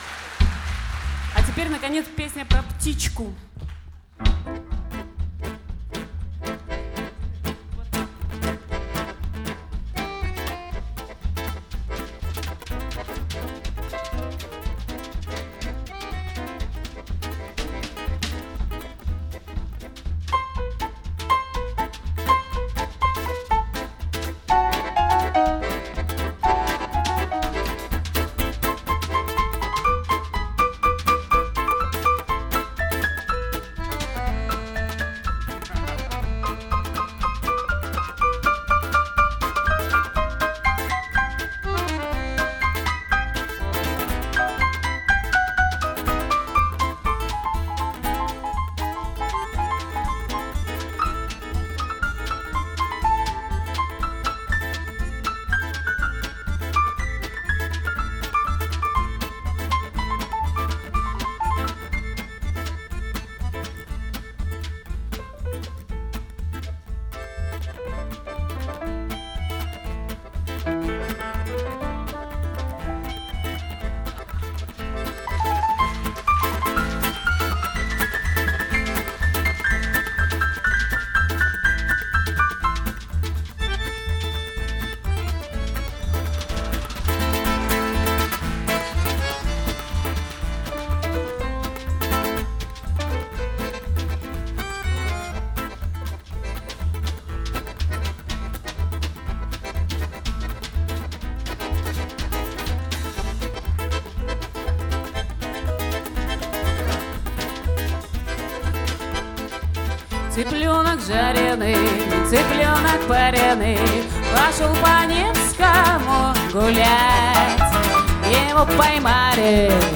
виолончель
баян